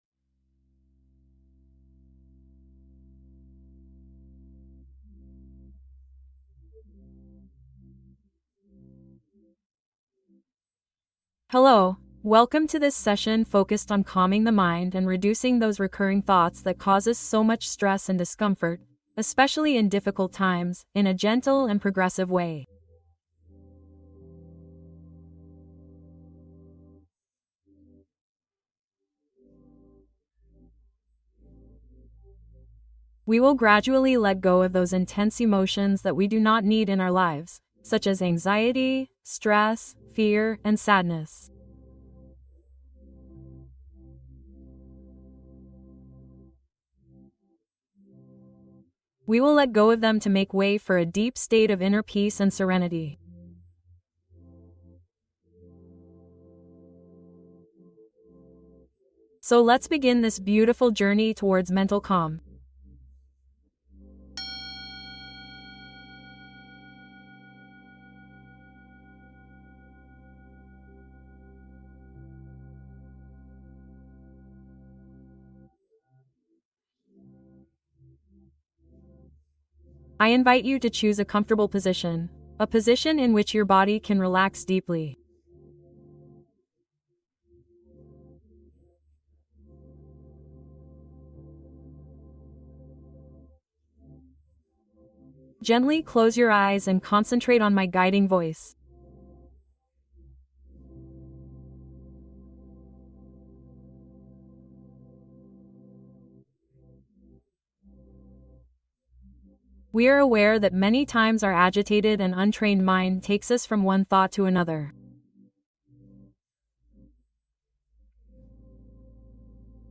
Meditación guiada para calmar ansiedad, estrés y miedo